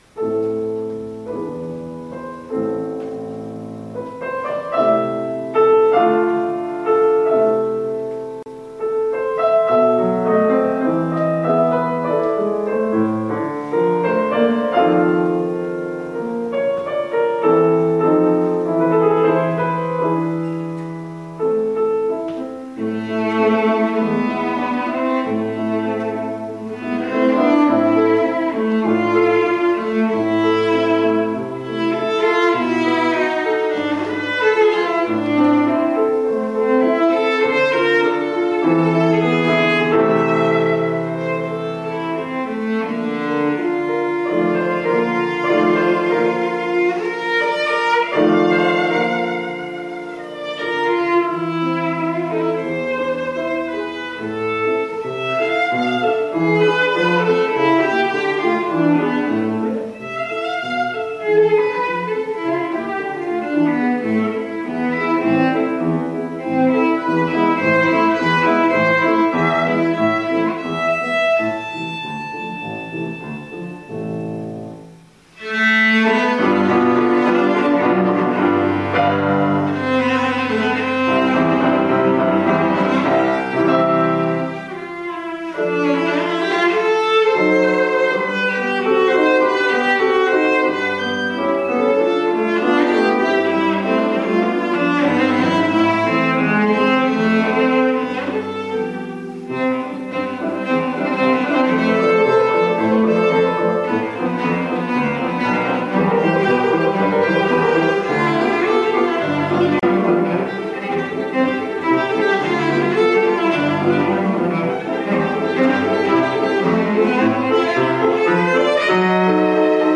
Style: Classical
cello